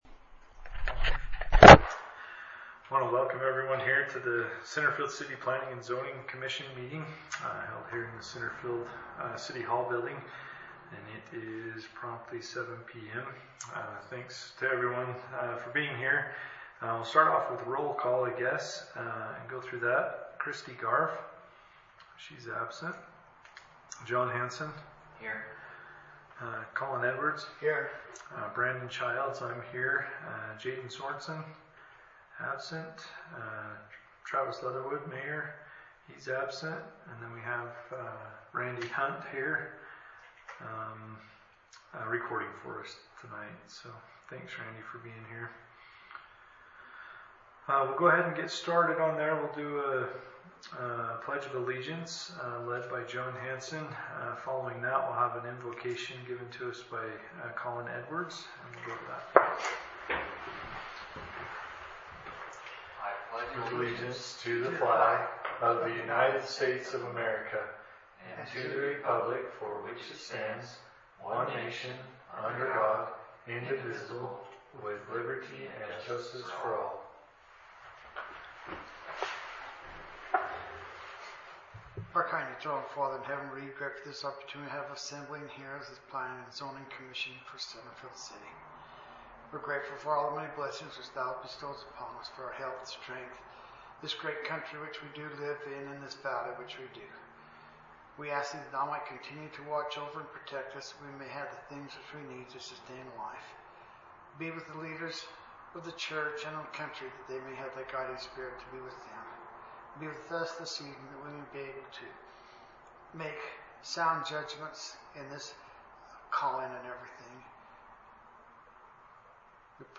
Notice is hereby given that the Planning Commission will hold a commission meeting on July 31, 2025 at the Centerfield City Hall, 130 South Main; the meeting will begin promptly at 7:00 p.m.